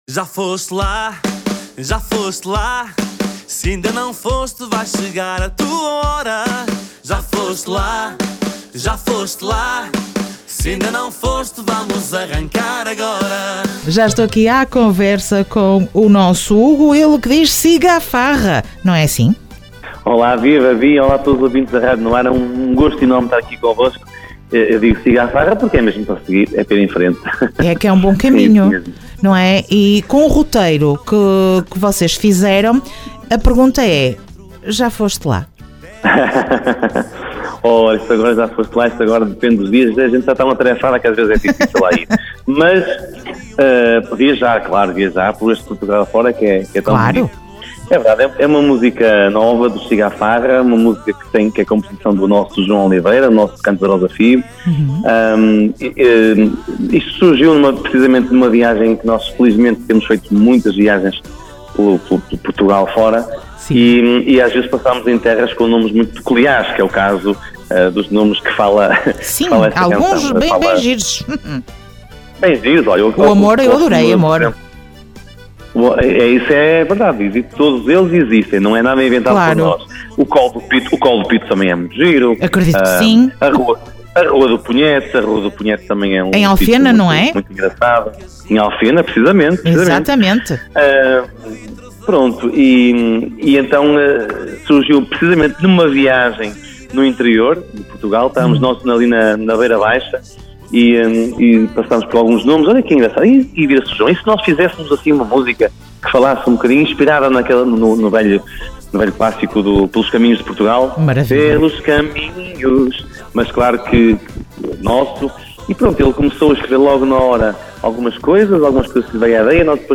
Entrevista aos Siga a Farra dia 24 de abril.